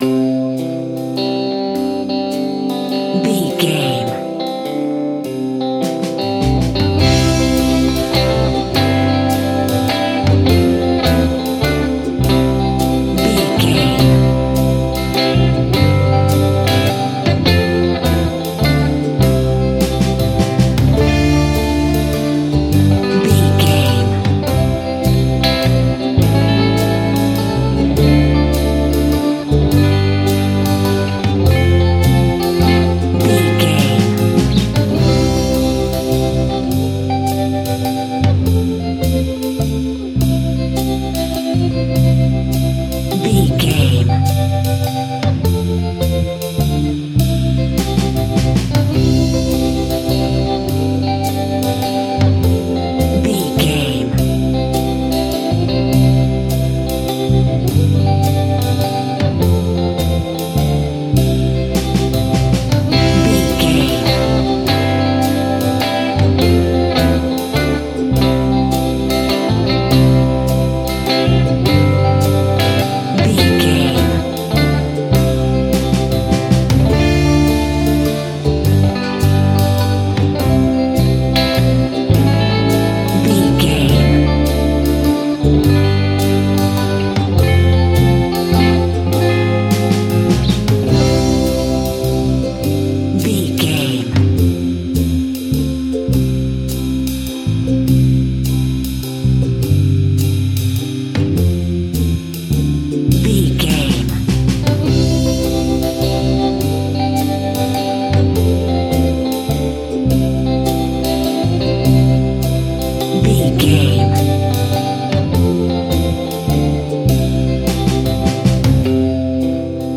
Ionian/Major
groovy
happy
electric guitar
bass guitar
drums
piano
organ